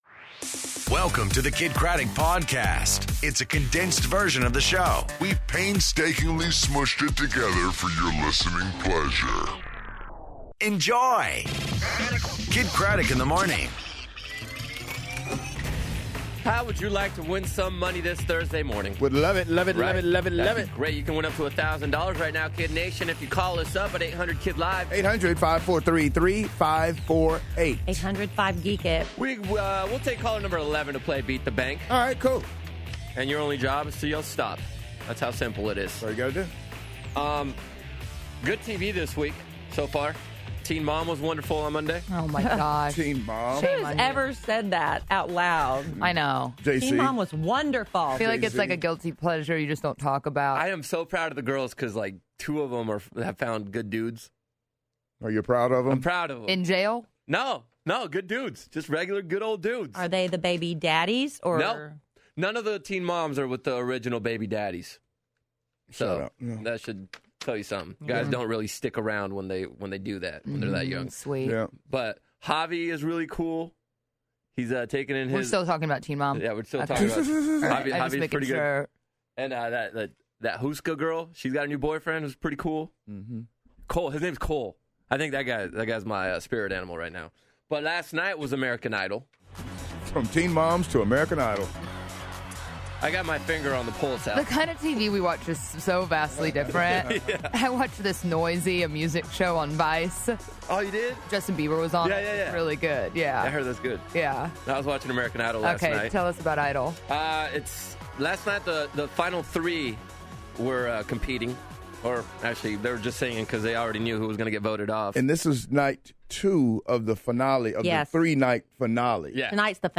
And Vanilla Ice In Studio